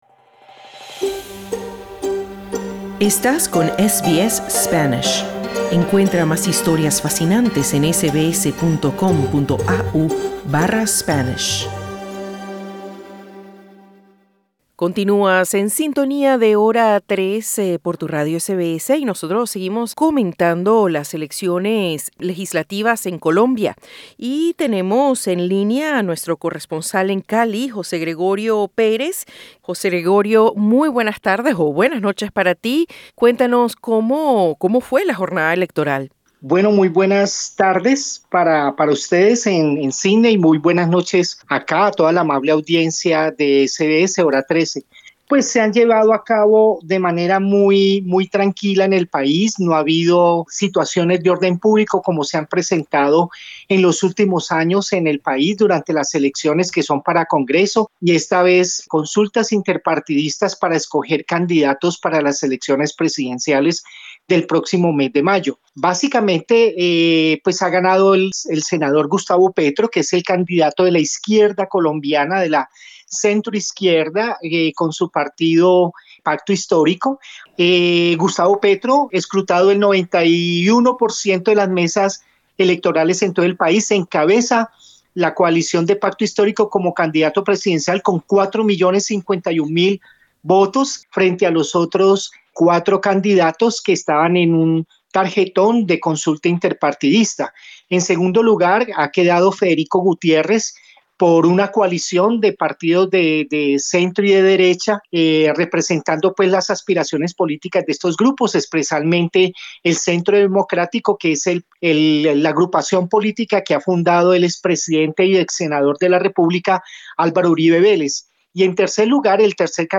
El senador y exguerrillero Gustavo Petro obtuvo este domingo la nominación presidencial de la izquierda colombiana con una amplia votación, que lo consolida como el favorito para vencer por primera vez a las fuerzas de derecha y centro en los comicios del 29 de mayo. Escucha el informe de nuestro corresponsal de SBS Spanish desde Colombia.